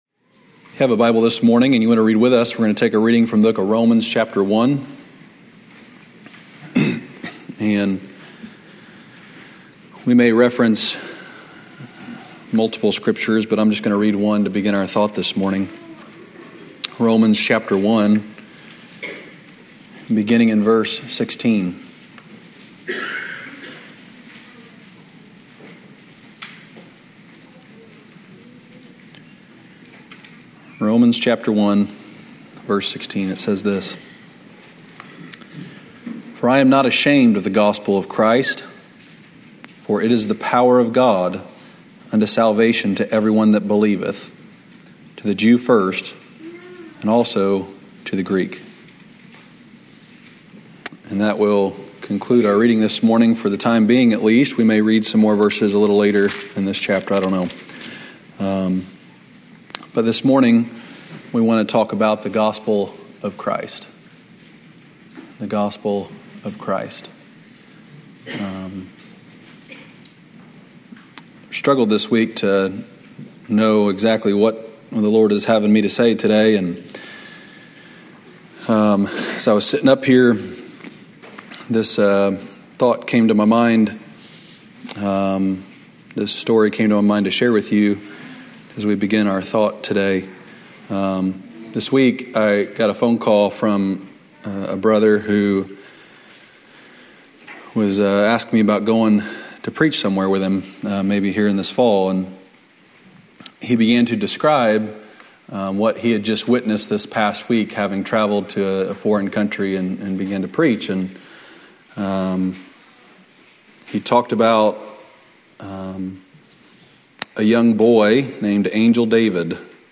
Wednesday night lesson from November 15, 2023 at Old Union Missionary Baptist Church in Bowling Green, Kentucky.